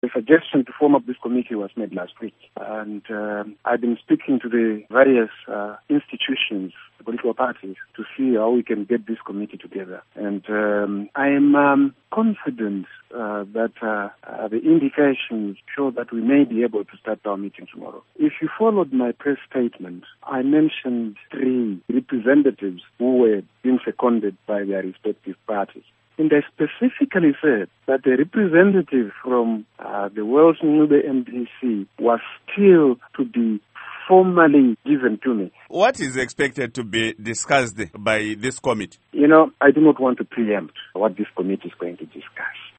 Interview With Minister Eric Matinenga